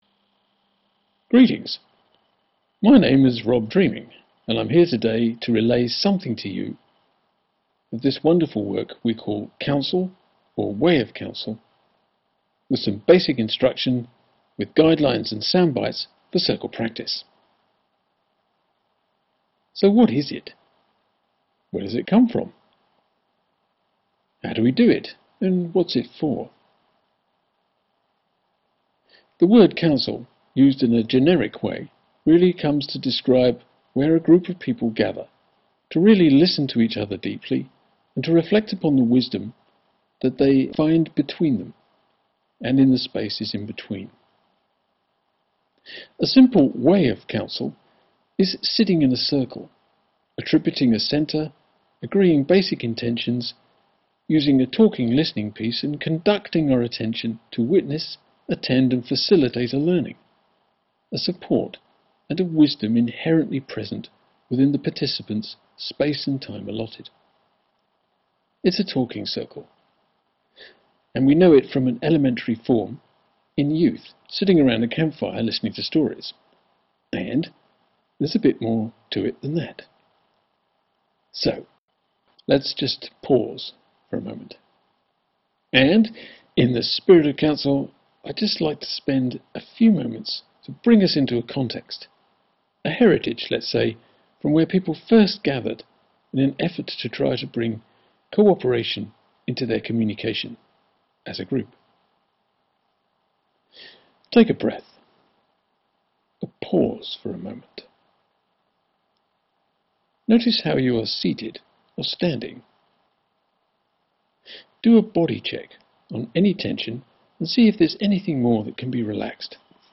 Introduction to Council – Basic instruction with guidelines and soundbytes for circle practice Listen here